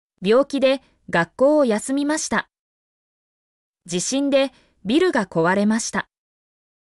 mp3-output-ttsfreedotcom-11_FHKi63BV.mp3